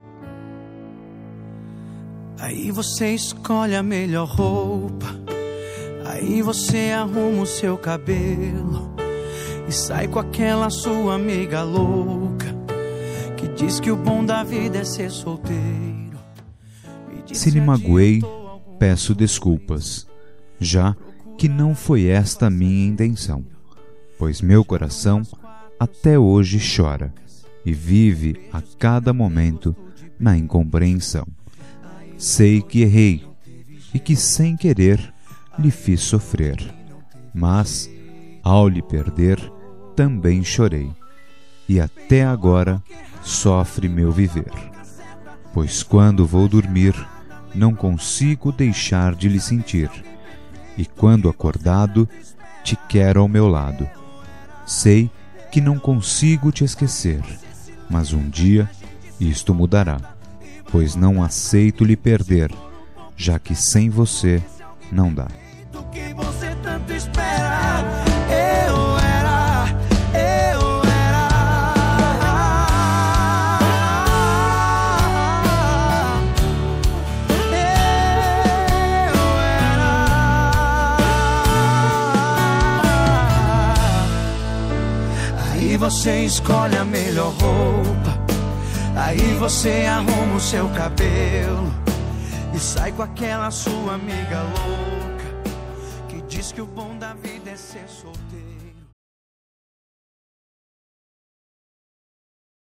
Telemensagem de Reconciliação Romântica – Voz Masculina – Cód: 944